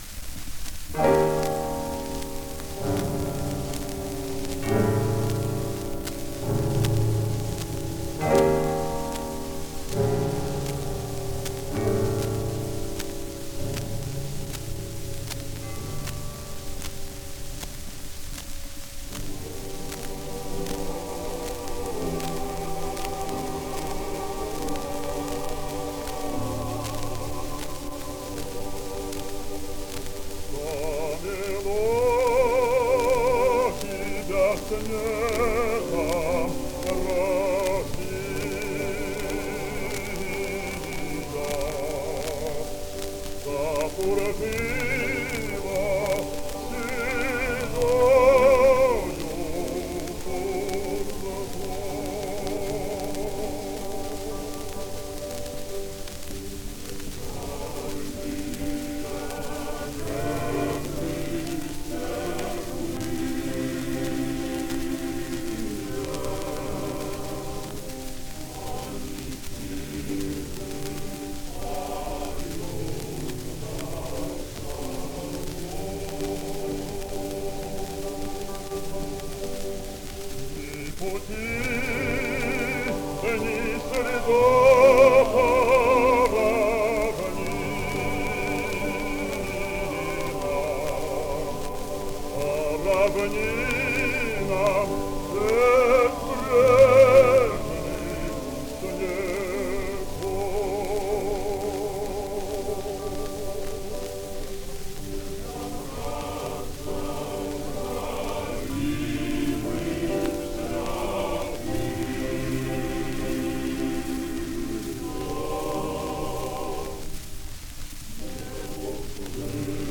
Prior to the First World War, and the Russian Revolution, the only place to hear Russian choral singing in America was in the Russian Orthodox Churches, which were built by Russian immigrants between 1880 and 1910.